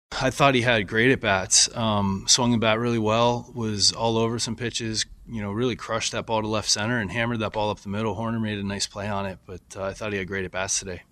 Kelly says Flores barreled up on the ball.